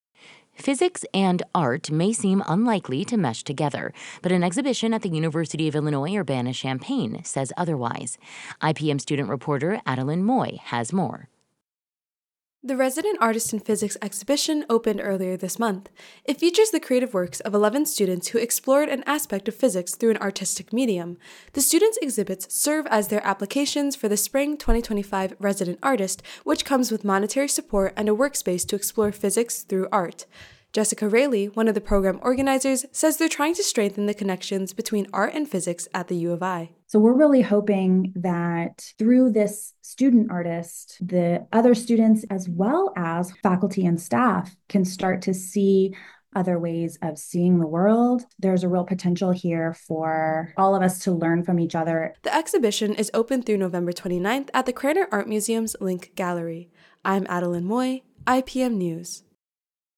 They sing an original song and paint to the expression of the music.
They assigned different melodies to planets, as well as assigned rhythms based on planetary orbits.